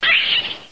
00216_Sound_bird.mp3